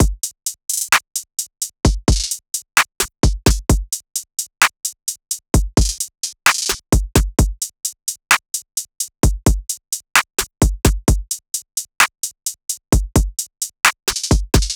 SOUTHSIDE_beat_loop_grey_full_130.wav